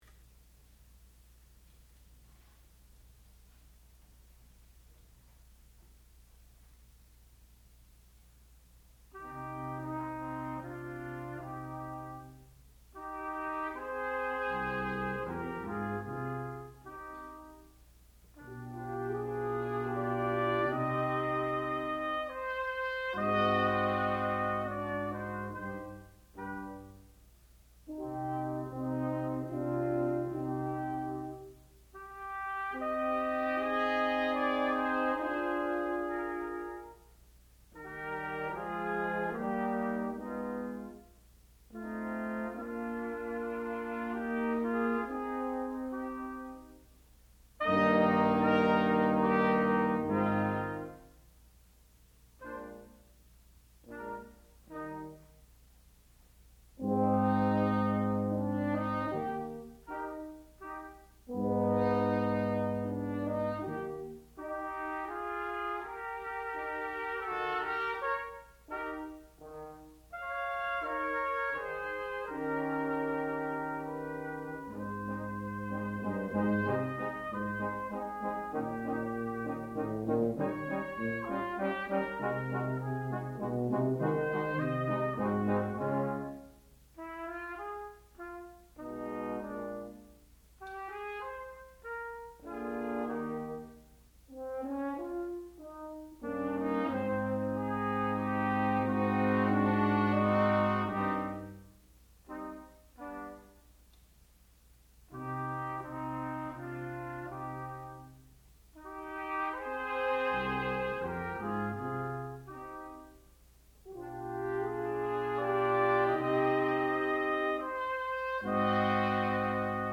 sound recording-musical
classical music
trumpet
horn